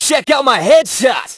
hotshot_kill_01.ogg